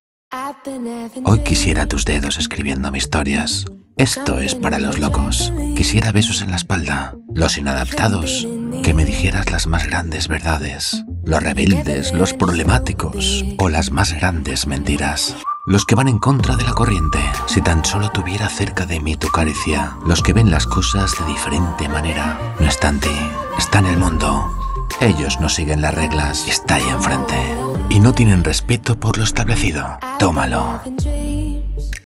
Precisa
Conversacional
Amistoso